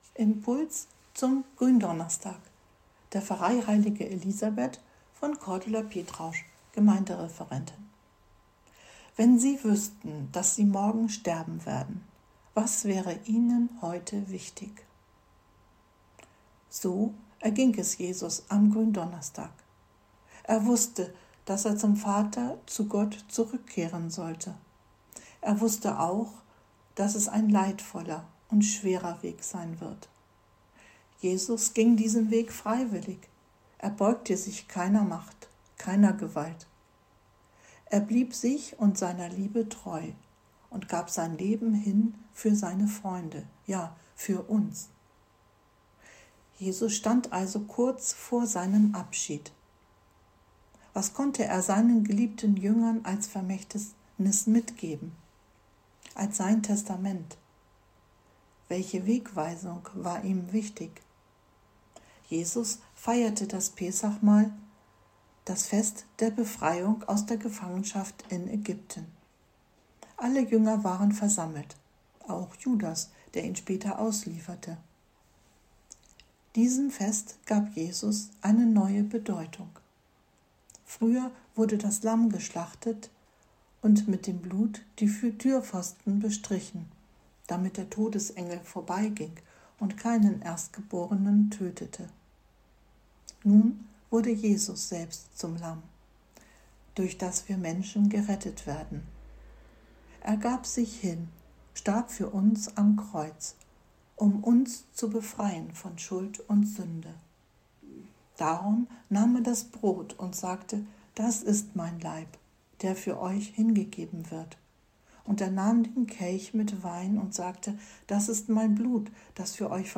Gründonnerstag – Geistlicher Impuls zum 14. April 2022
Geistlicher-Impuls-zum-Gruendonnerstag.mp3